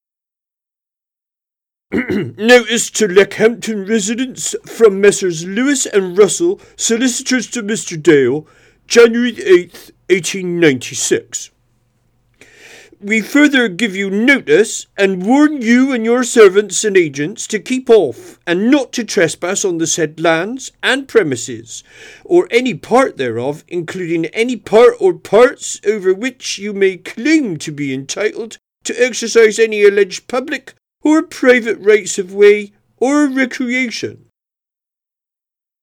Regional and Foreign Accents